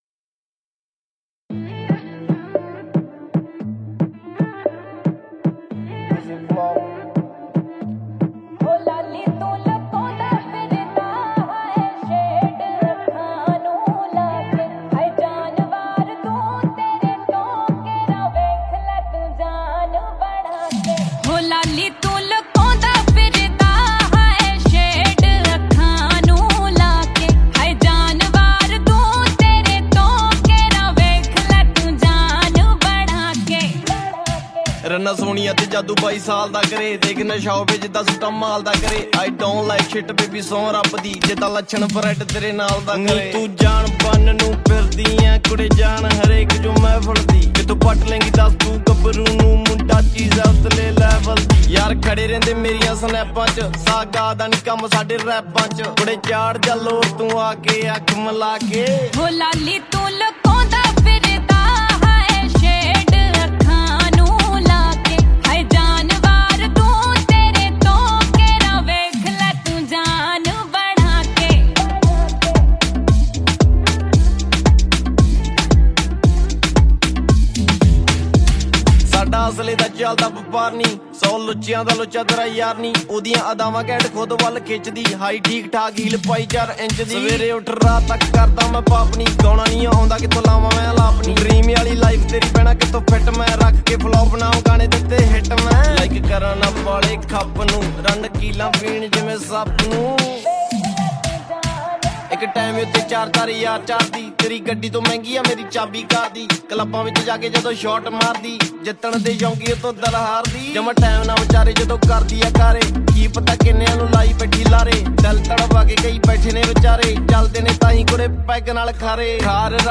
Category New Punjabi Song 2023 Singer(s